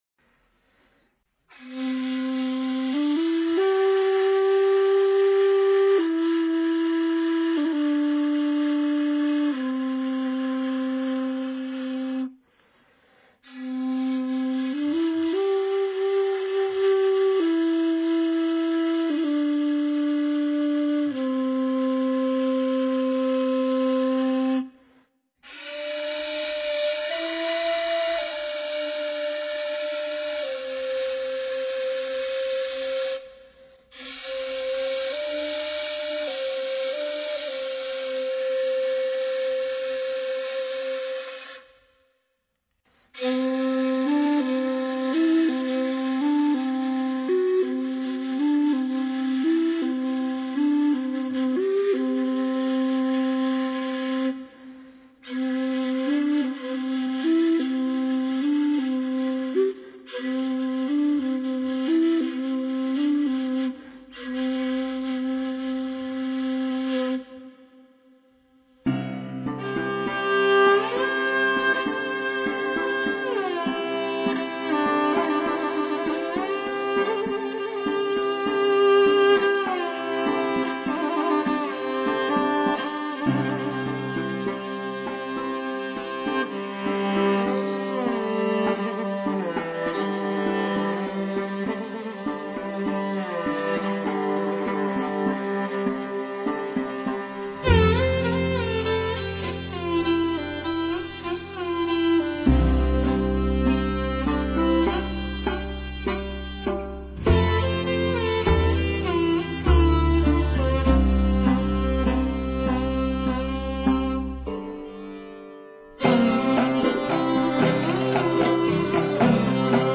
蒙古独特的“呼麦”唱法，震古烁今，穿越时空，绝对神奇；
近乎失传的“胡笳”演奏，源远流长，起死还生，绝无仅有；
悠扬激越的“马头琴”声，雄辉悦耳，质感鲜明，绝对正宗。